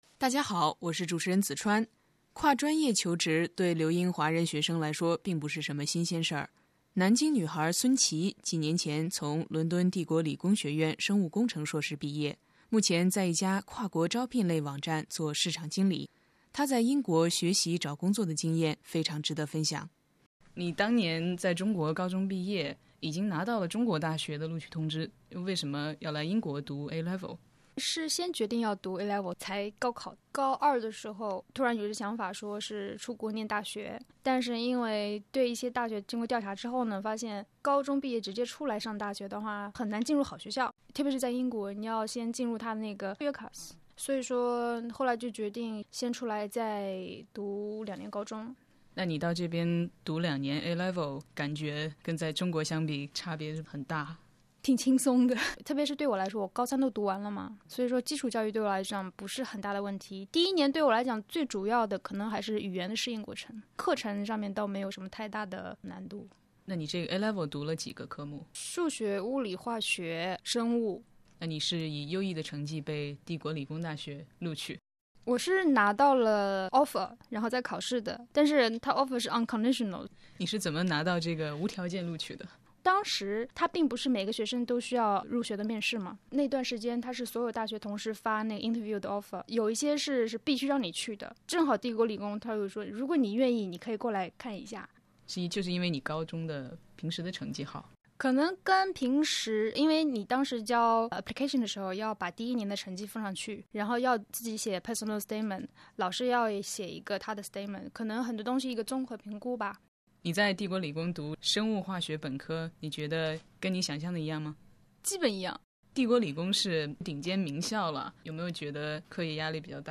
专访学生、教师及教育专家，提供权威、实用的留学信息和解答。